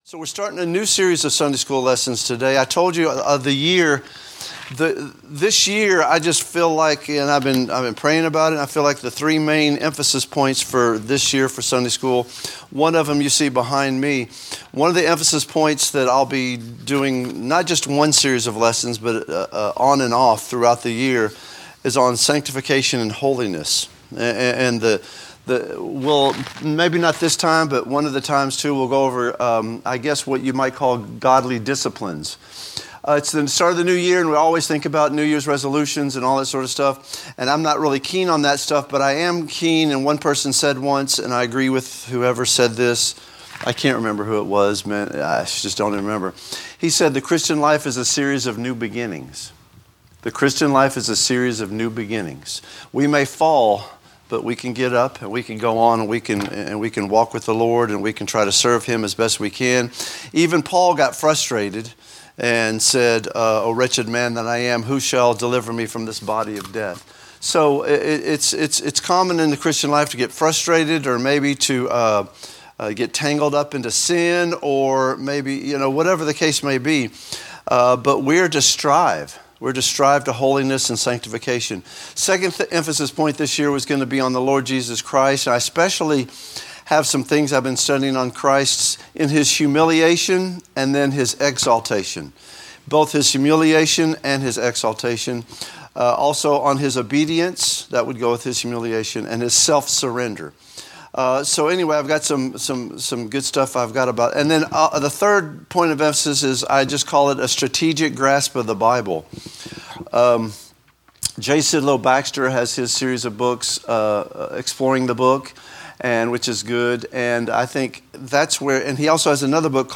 Teaching